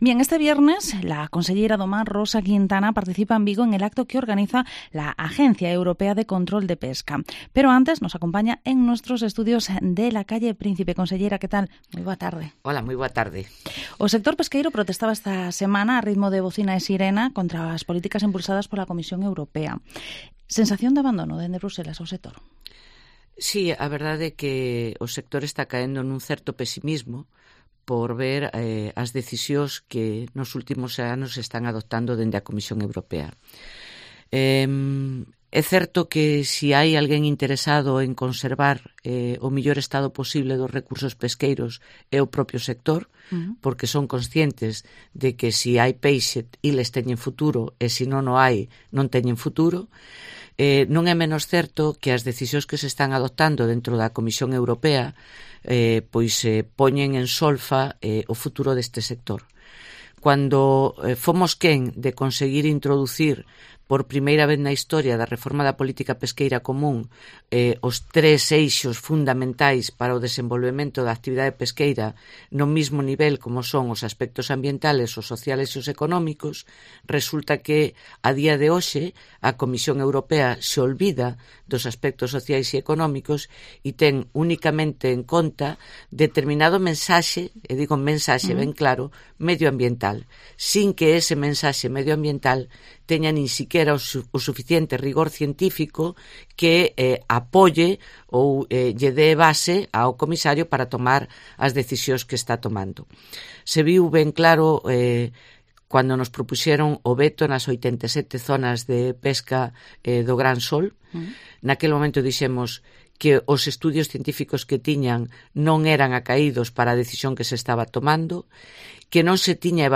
Rosa Quintana, conselleira do Mar, habla en Cope Vigo sobre la situación del sector con Bruselas
Entrevista